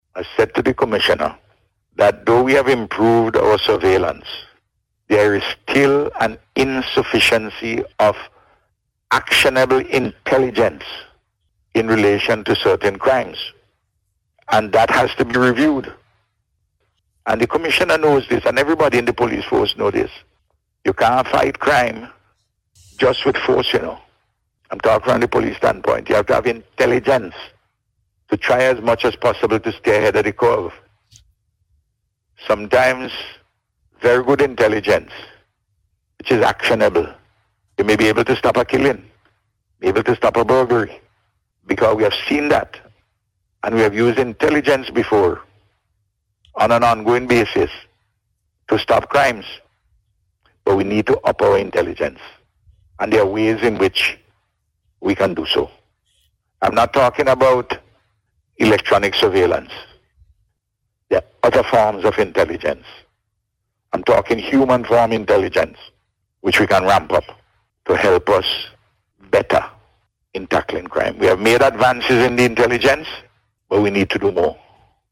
The Prime Minister, who is also the Minister of National Security said on Radio yesterday that the Police Force must find better strategies to fight crime.